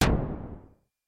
Fender Chroma Polaris Storm Drum " Fender Chroma Polaris Storm Drum E4 (64 E34FZO)
标签： MIDI网速度52 E4 MIDI音符-64 挡泥板-色度北极星 合成器 单票据 多重采样
声道立体声